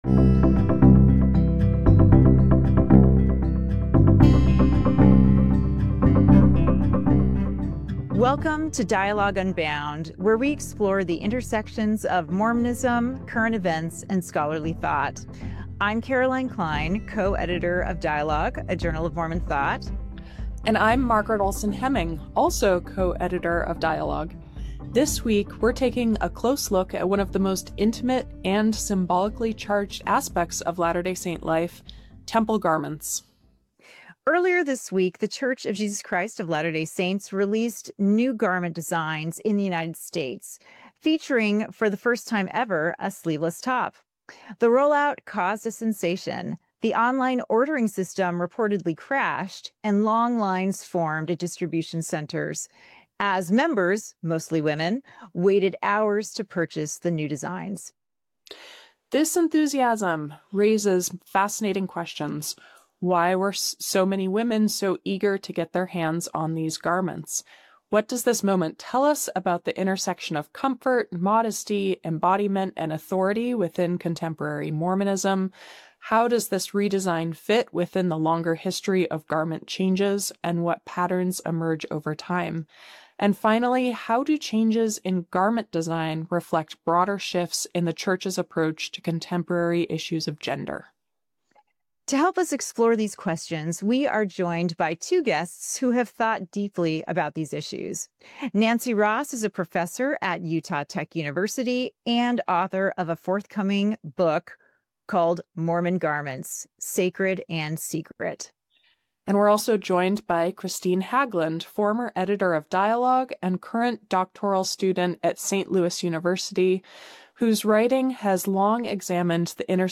A conversation about the recent garment redesign and the cultural meaning of temple garments